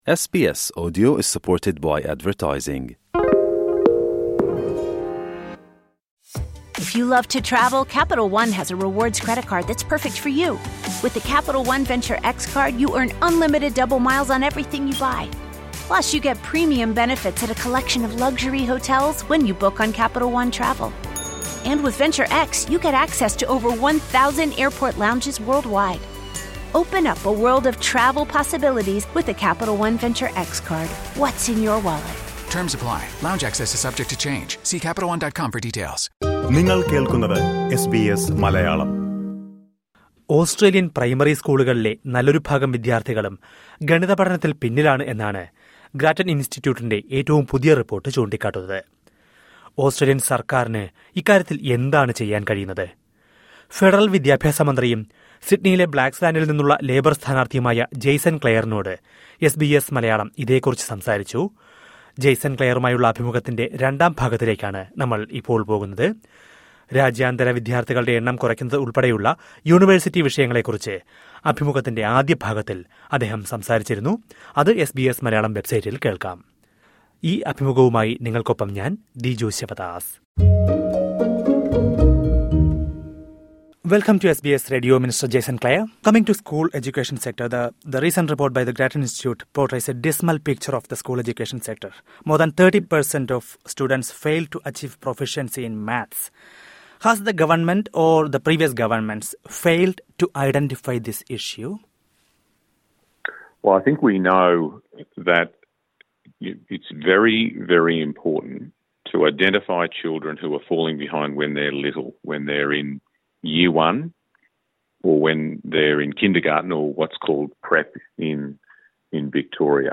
ഓസ്‌ട്രേലിയന്‍ പ്രൈമറി സ്‌കൂളുകളിലെ നല്ലൊരു ഭാഗം വിദ്യാര്‍ത്ഥികളും ഗണിത പഠനത്തില്‍ പിന്നിലാണ് എന്നാണ് ഗ്രാറ്റന്‍ ഇന്‍സ്റ്റിറ്റ്യൂട്ടിന്റെ റിപ്പോര്‍ട്ട് ചൂണ്ടിക്കാട്ടുന്നത്. ഓസ്‌ട്രേലിയന്‍ സര്‍ക്കാരിന് ഇക്കാര്യത്തില്‍ എന്താണ് ചെയ്യാന്‍ കഴിയുന്നത്. ഫെഡറല്‍ വിദ്യാഭ്യാസമന്ത്രിയും, ലേബര്‍ സ്ഥാനാര്‍ത്ഥിയുമായ ജേസന്‍ ക്ലെയറിനോട് എസ് ബി എസ് മലയാളം സംസാരിക്കുന്നത് കേള്‍ക്കാം...